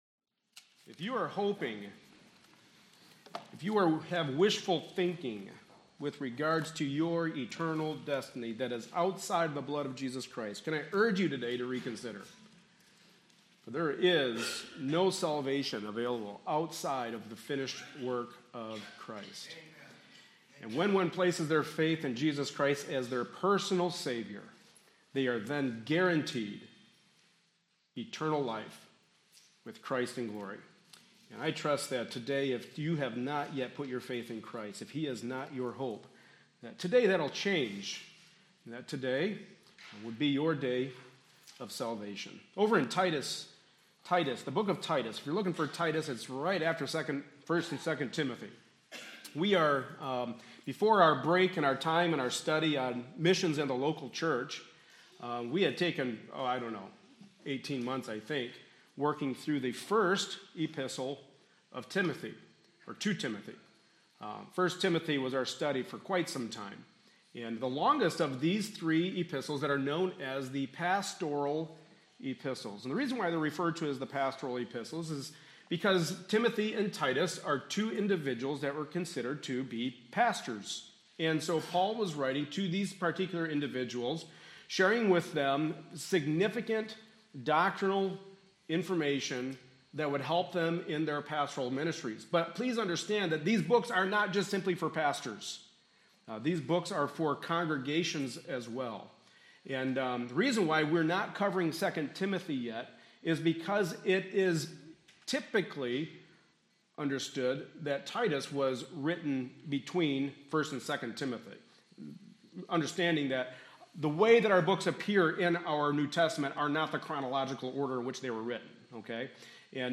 Titus Service Type: Sunday Morning Service Related Topics